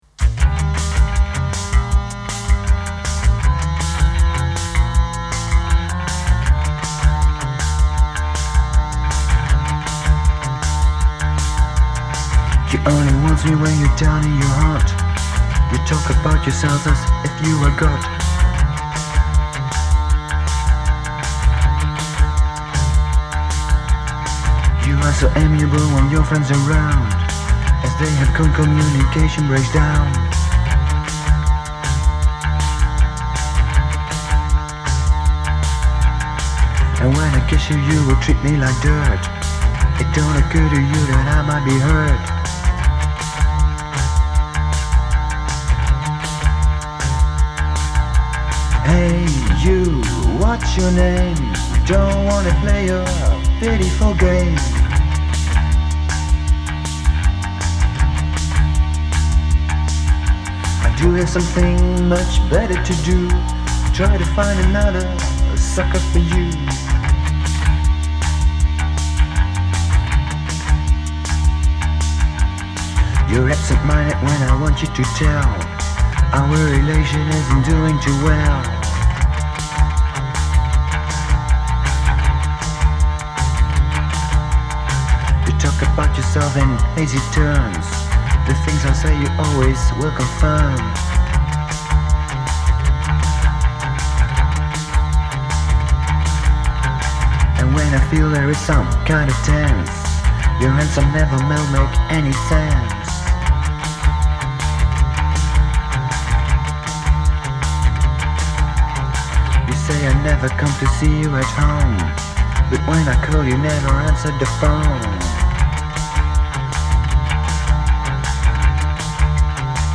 you play a bit, loop it and play something else on top.